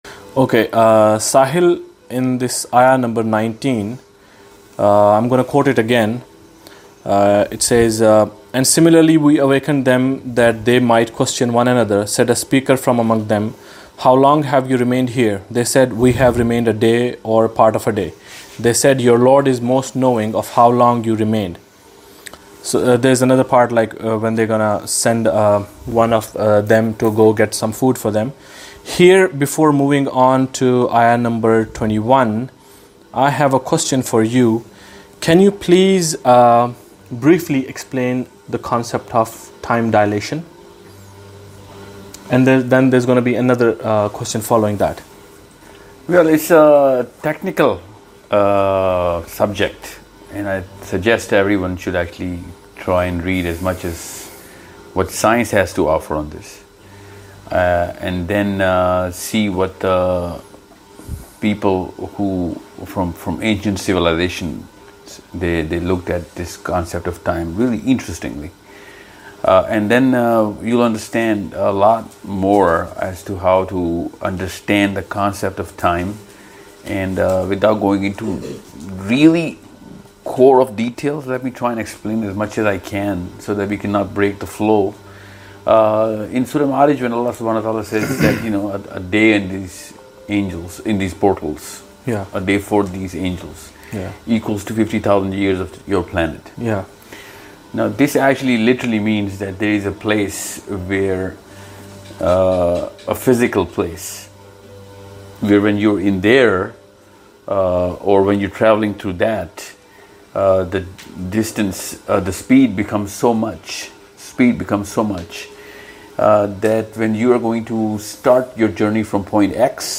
Al Kahf Part 7 of 15 ： Finding Dajjal in Surah Kahf (A detailed scientific commentary in English).mp3